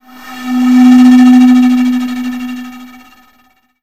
Worried.wav